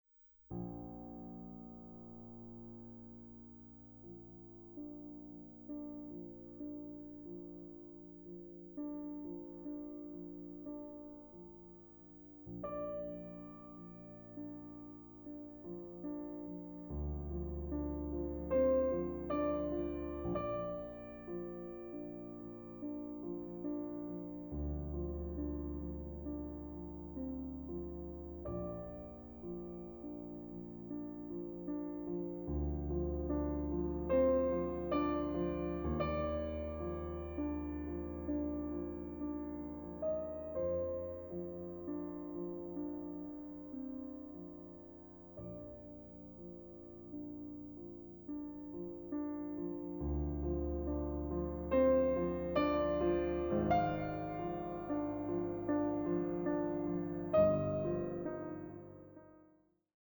Klavierwerken aus sechs Jahrhunderten